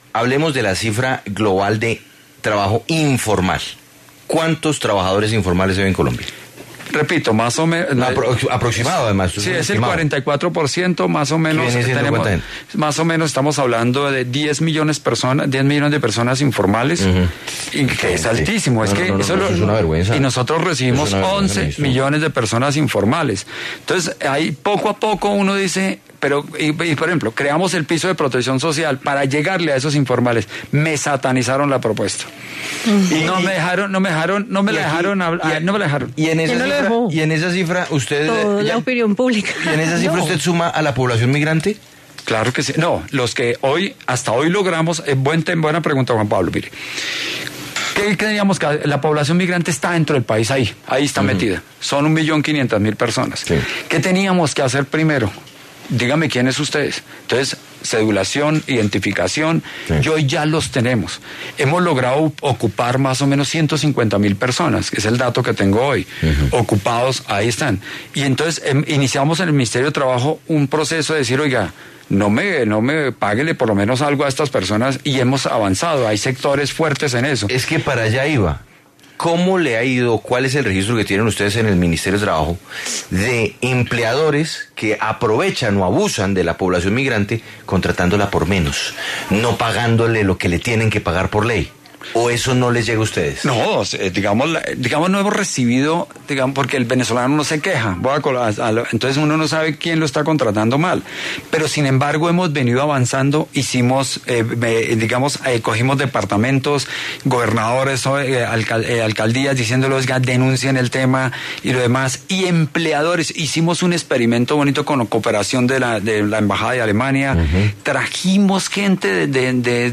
Ángel Custodio Cabrera, el ministro del Trabajo, conversó en Sigue La W sobre las medidas para contrarrestar el empleo informal en el país.
En el encabezado, sus declaraciones sobre su propuesta del piso de protección social.